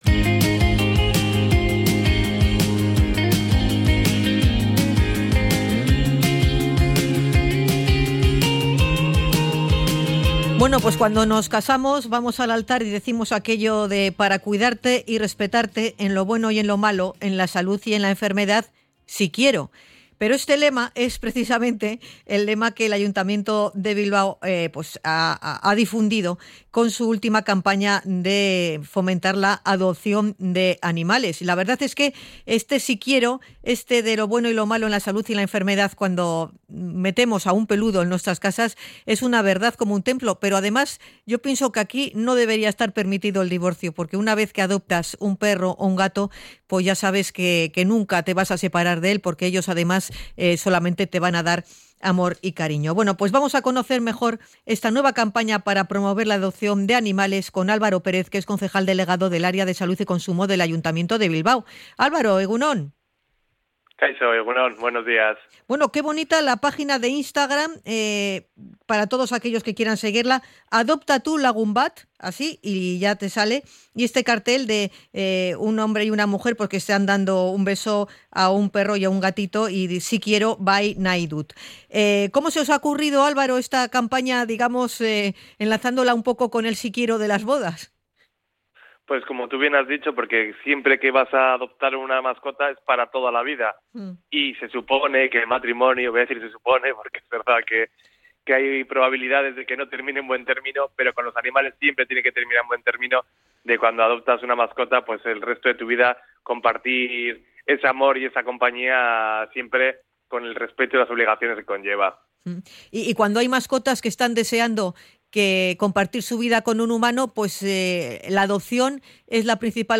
Hablamos con Álvaro Pérez, concejal de Salud y Consumo